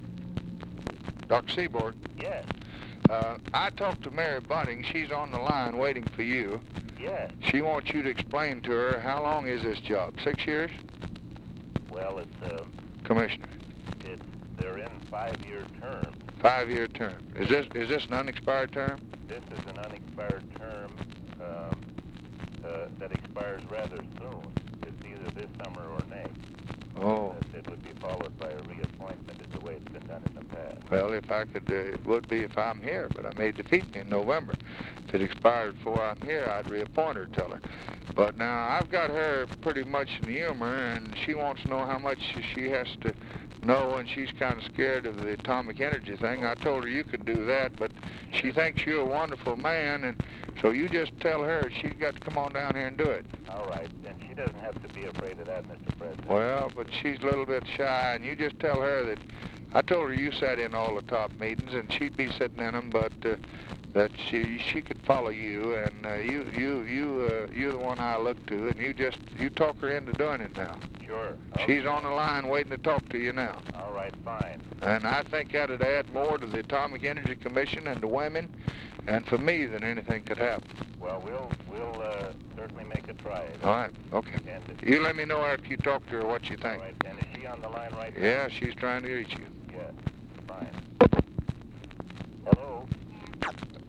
Conversation with GLENN SEABORG, March 21, 1964
Secret White House Tapes